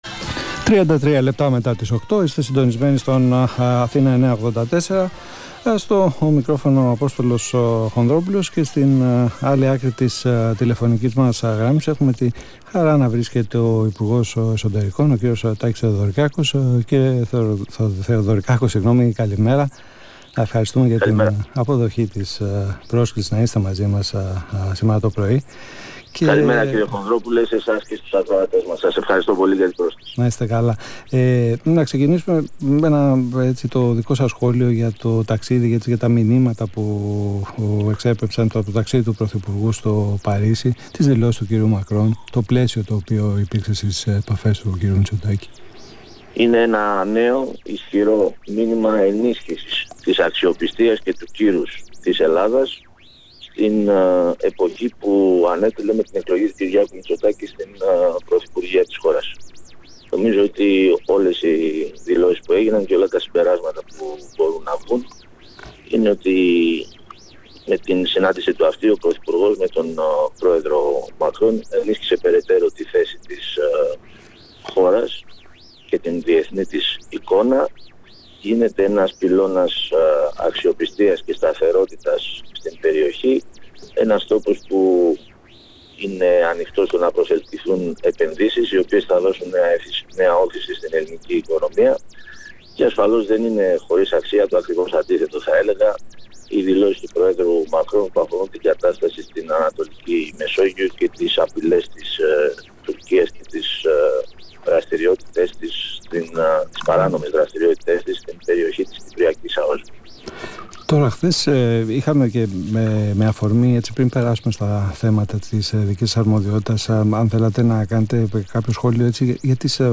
Συνέντευξη του ΥΠΕΣ Τάκη Θεοδωρικάκου στον ρ/σ “Αθήνα 9.84” (Ηχητικό)